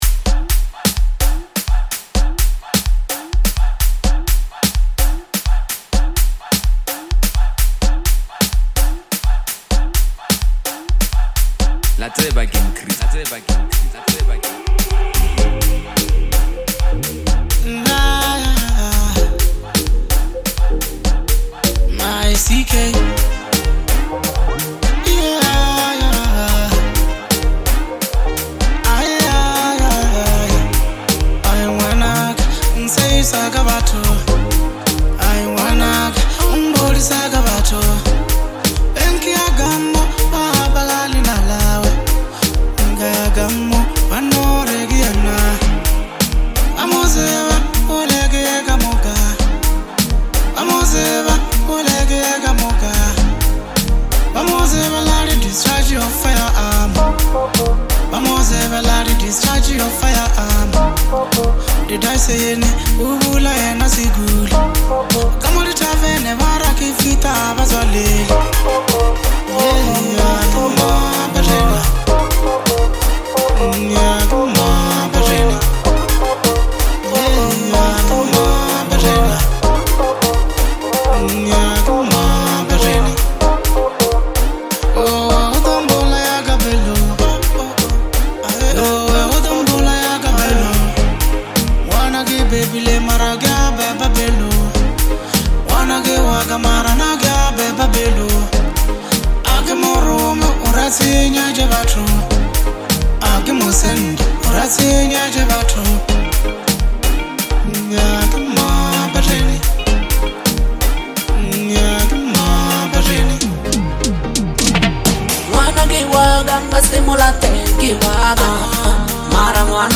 Lekompo
making it a fusion of traditional and urban sound.